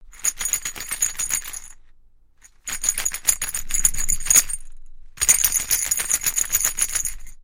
Коллекция включает разные варианты: от мягких перезвонов до более ритмичных звуков.
Звук детской погремушки – старая игрушка